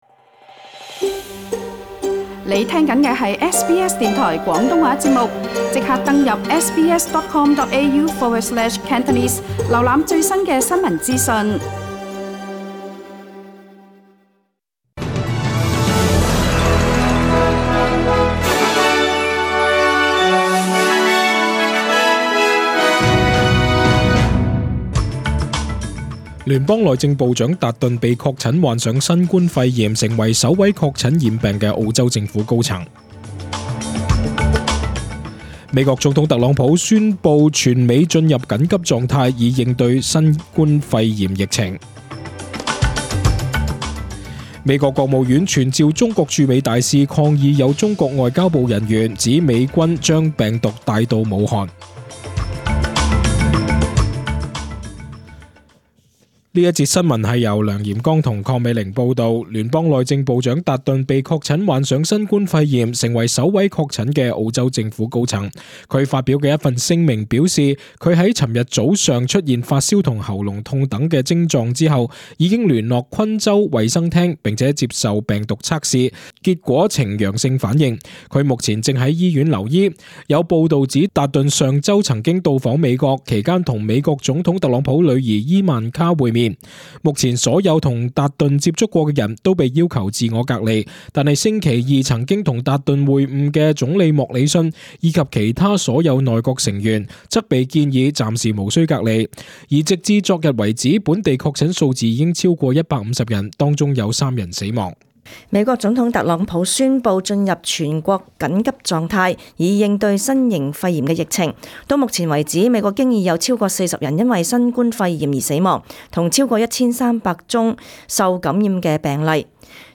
请收听本台为大家准备的详尽早晨新闻。
SBS 廣東話節目中文新聞 Source: SBS Cantonese